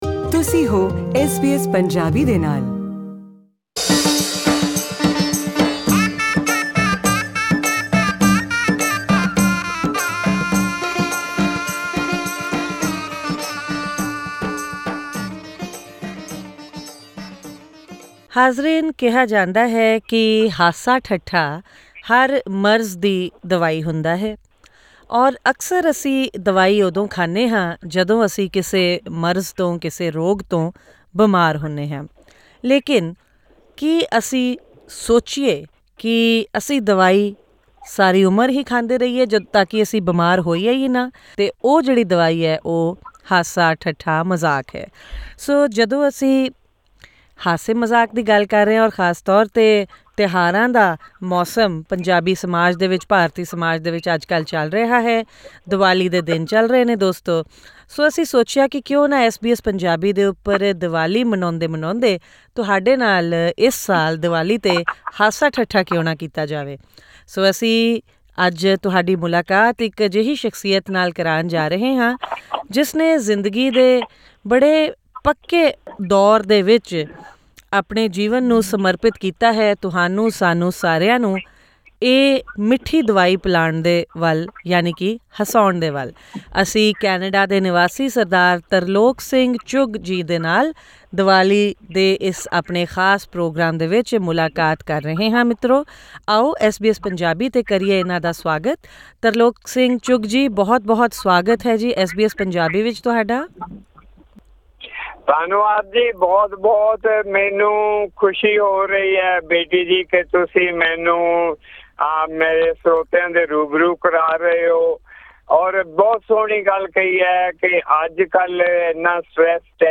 Known for his singsong style of telling jokes, this Canadian-Punjabi septuagenarian has quickly become a household name in Punjabi cyberspace.